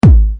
UI礼物弹窗数字弹动.MP3